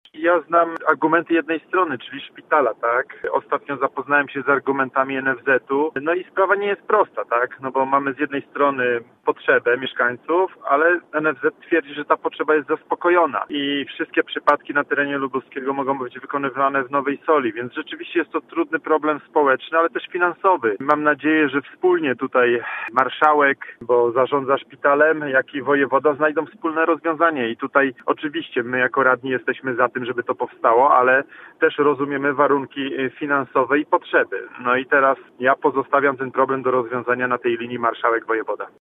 Stanowisko popierające utworzenie w Gorzowie kardiochirurgii radni przyjęli jednogłośnie. To trudny temat, biorąc pod uwagę NFZ, ale dobro mieszkańców jest na pierwszym miejscu – powiedział nam Sebastian Pieńkowski, wiceprzewodniczący Rady Miasta z klubu PiS.